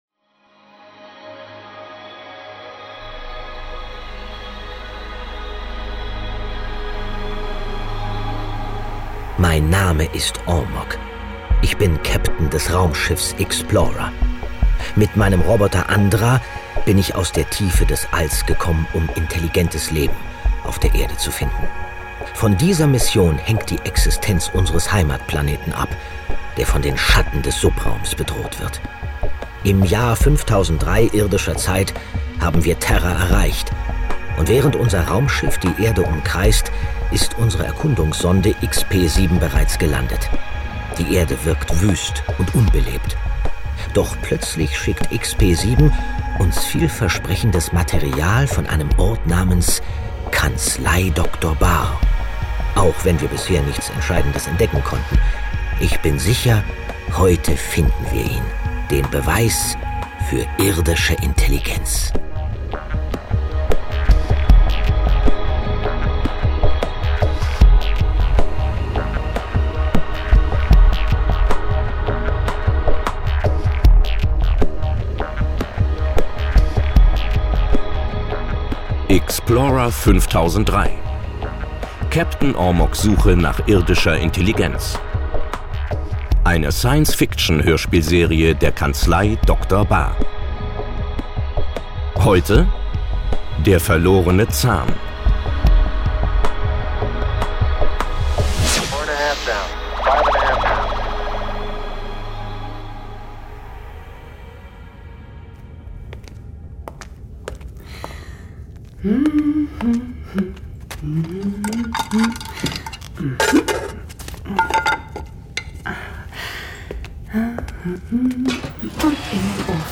Vor kurzem ist die Science-Fiction-Hörspiele-Serie “Ixplorer 5003” der Kanzlei Dr. Bahr gestartet, in der in zwölf Hörspielfolgen von jeweils ca. 15 Minuten ein Dutzend Fragen des Online-Rechts an Beispielen erläutert und kommentiert werden.